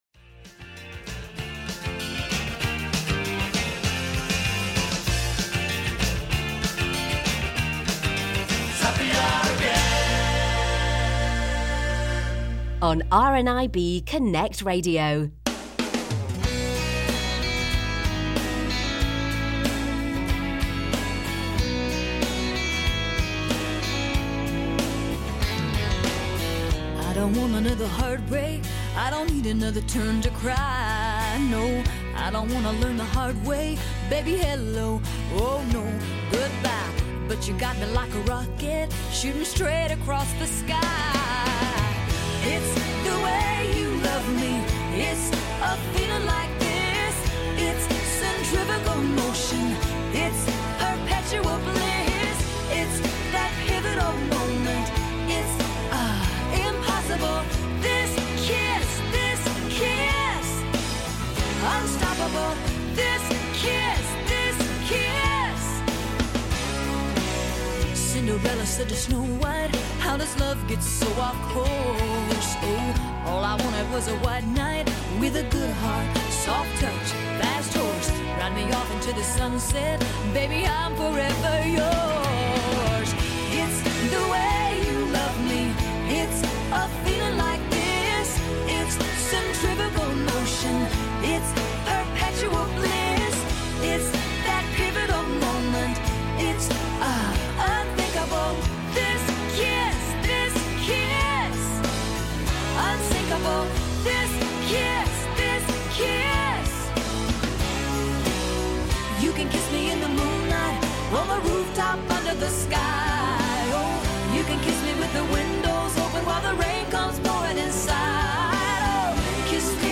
In a special long conversation, they discuss his new book 'My Hygge Home' and whether we can become architects of our own happiness by re-shaping the space around us. Plus find out about a new support group from RNIB and how it helps connect blind and partially sighted people in a small town in in the East Midlands.
The Happy Hour Podcast is our trimmed down version of this hour-long show, so if you'd like to listen to the full show with all the amazing songs featured, catch a new episode of the Happy Hour on RNIB Connect Radio Mondays at 1 PM, with a repeat Thursday at 6 PM and Sunday at 10 am.